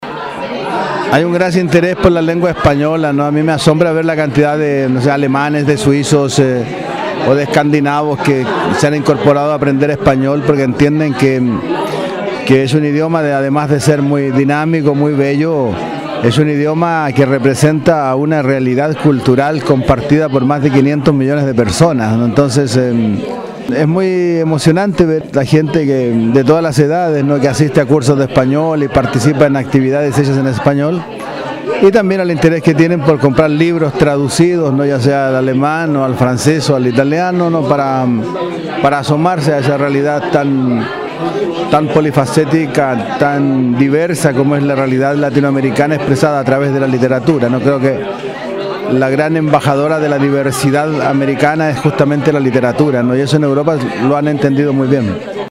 Luis Sepúlveda, escritor chileno, en entrevista con swissinfo.